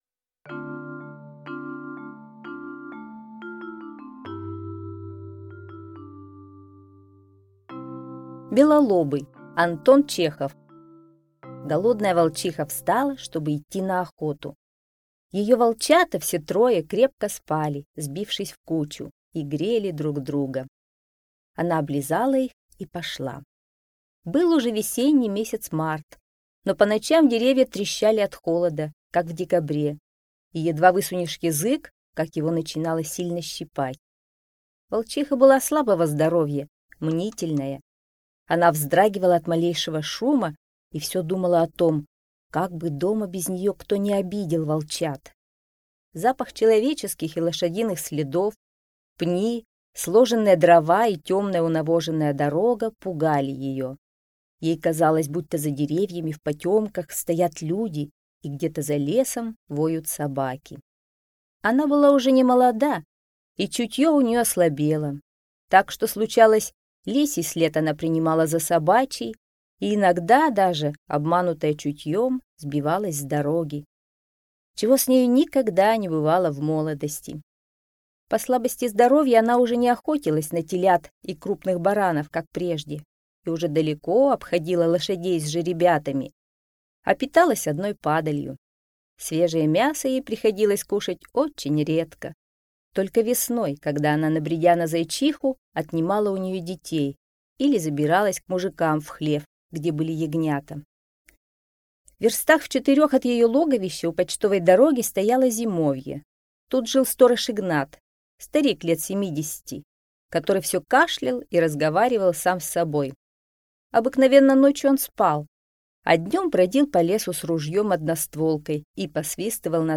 Белолобый - аудио рассказ Чехова А.П. Рассказ про белолобого щенка, которая голодная волчиха по ошибке вытащила из овчарни.